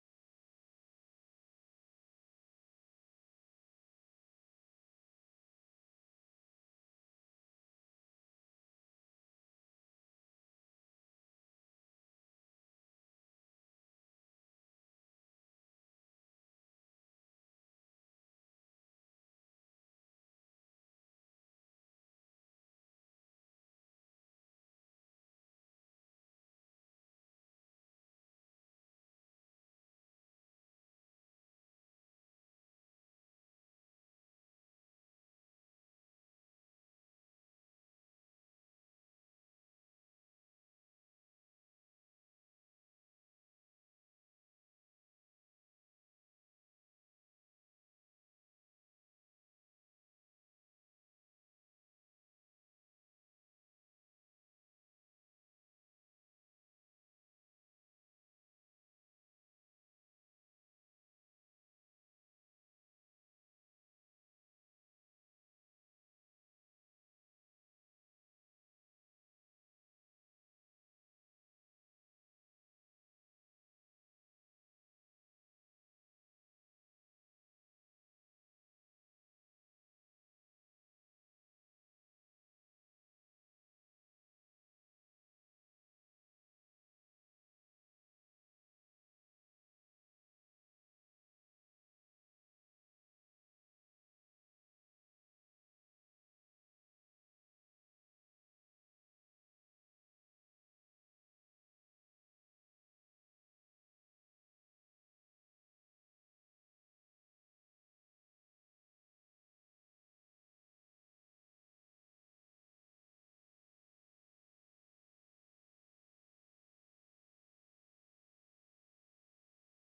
決算報告会